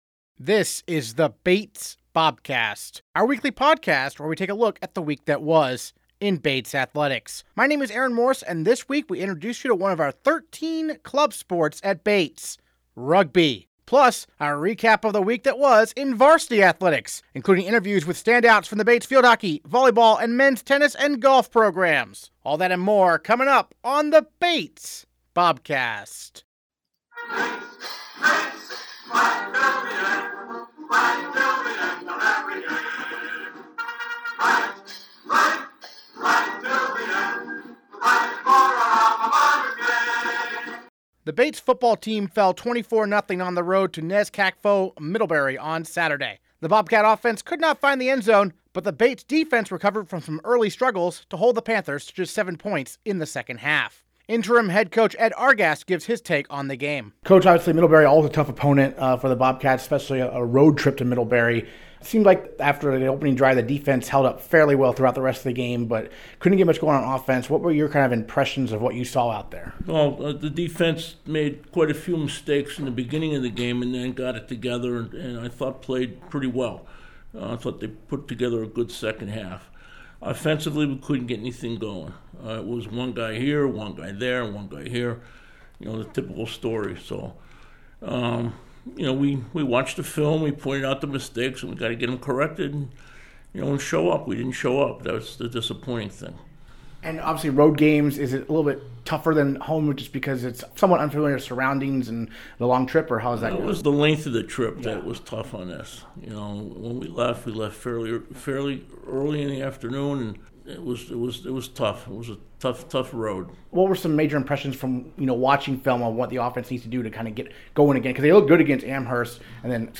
Plus, our recap of the week that was in varsity athletics, including interviews with standouts from the Bates field hockey, volleyball and men's tennis and golf programs.